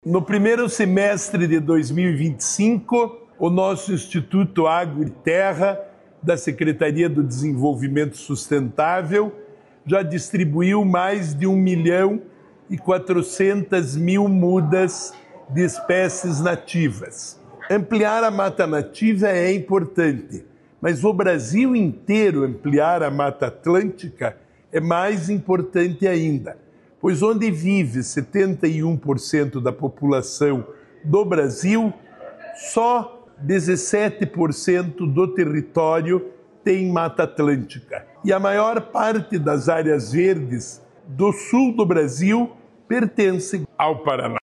Sonora do secretário do Desenvolvimento Sustentável, Rafael Greca, sobre a distribuição de mudas no primeiro semestre de 2025